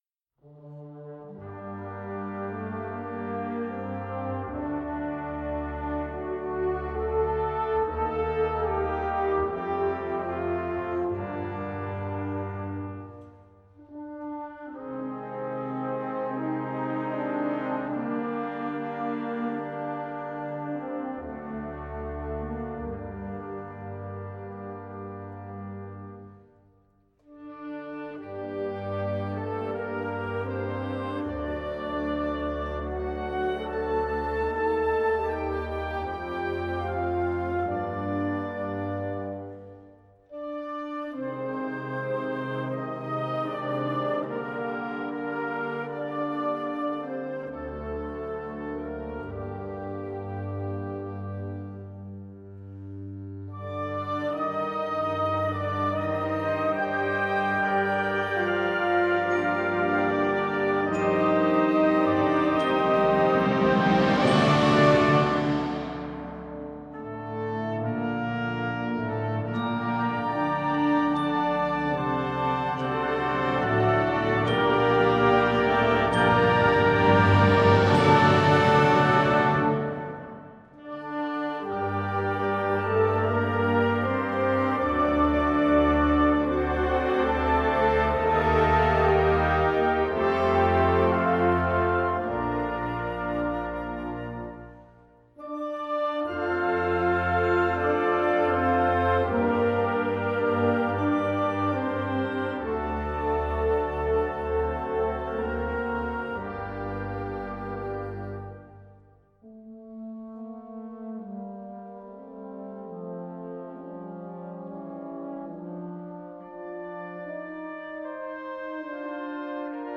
Swedish folksong